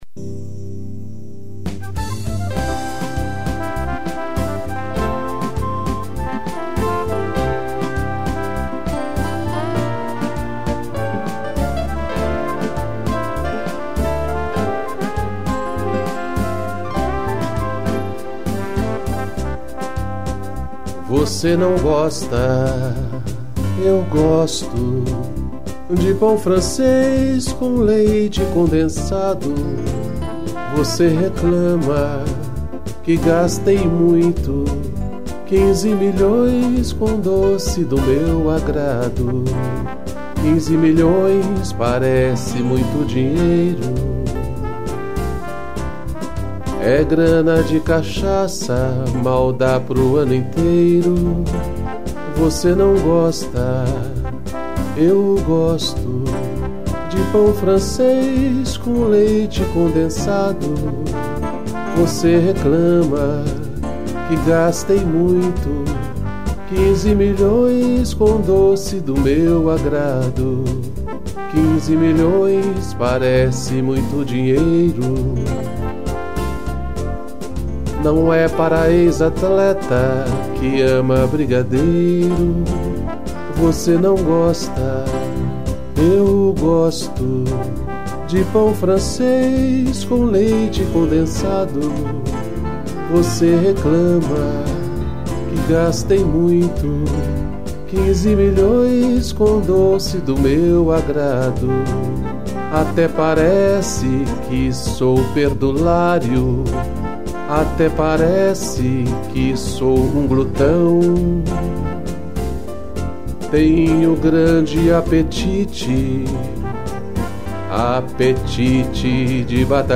piano e trombone